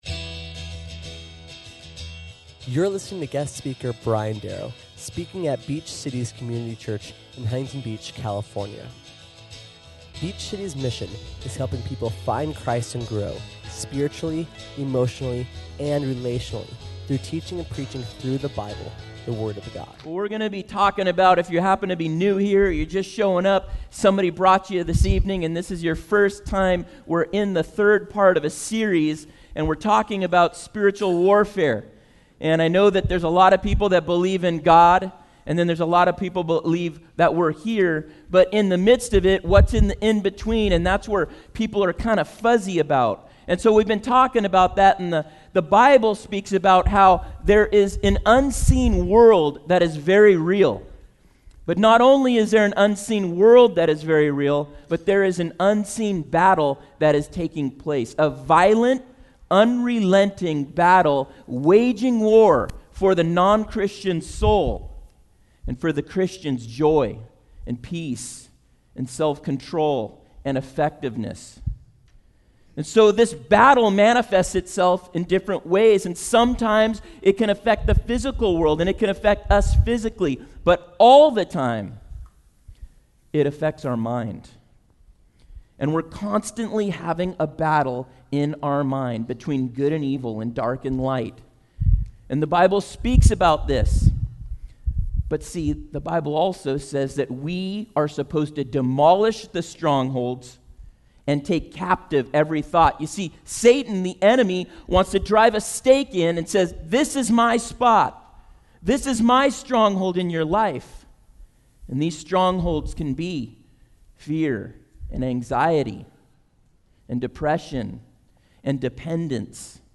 We learn what they do and how we can use them. SERMON AUDIO: SERMON NOTES: